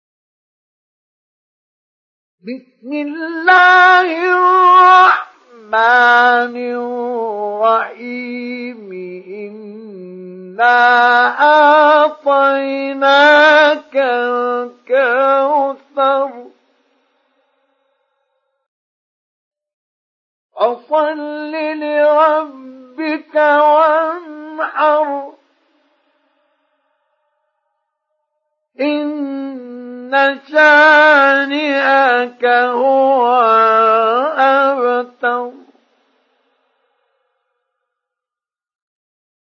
سُورَةُ الكَوۡثَرِ بصوت الشيخ مصطفى اسماعيل